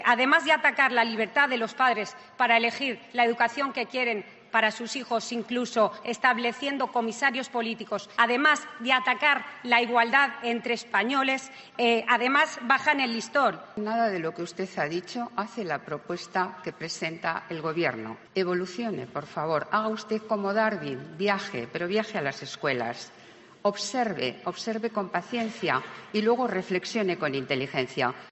Escucha el enfrentamiento entre la ministra Celaá y la diputada popular Belén Hoyo
Lo ha señalado en el pleno del Congreso a respuestas a la diputada popular Belén Hoyo, que le ha preguntado cómo valora la autoestima del Gobierno.